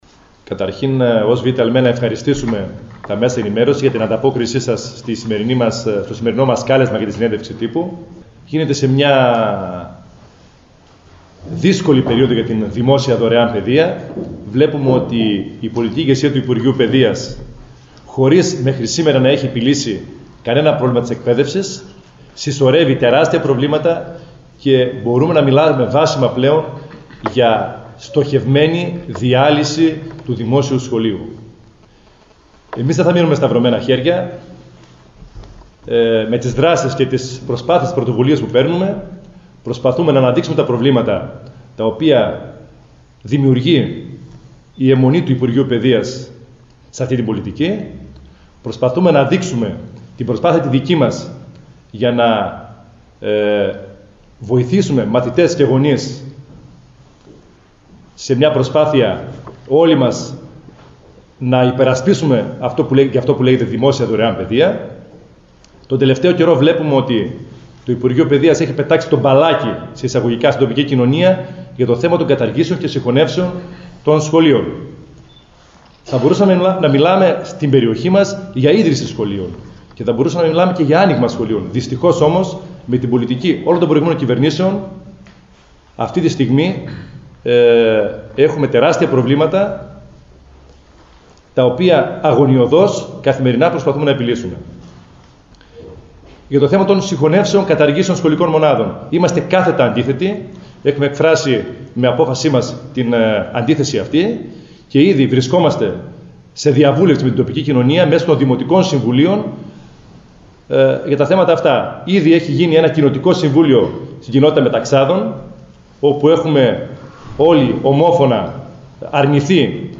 Συνέντευξη τύπου